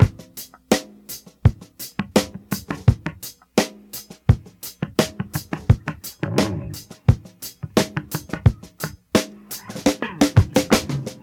86 Bpm Rock Breakbeat G# Key.wav
Free drum loop sample - kick tuned to the G# note.
86-bpm-rock-breakbeat-g-sharp-key-1Wa.ogg